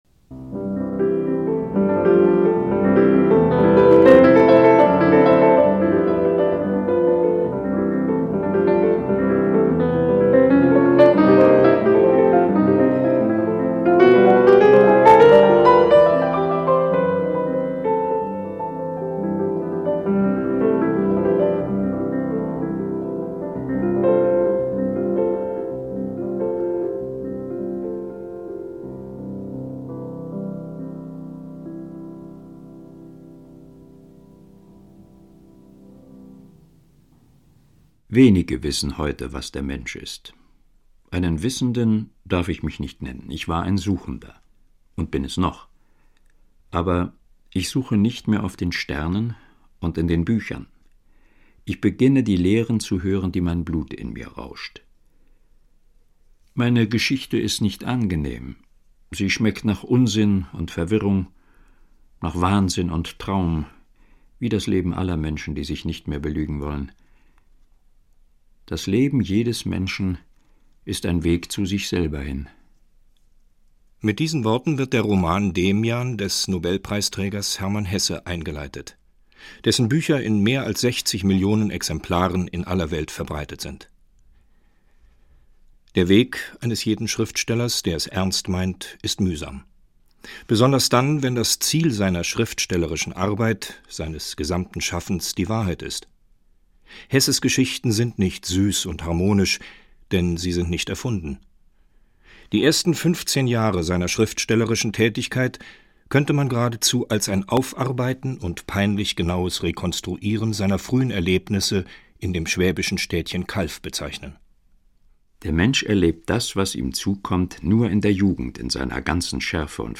Audio des Hörstücks